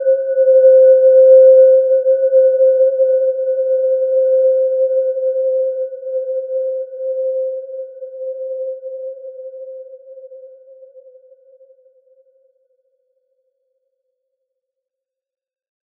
Gentle-Metallic-3-C5-p.wav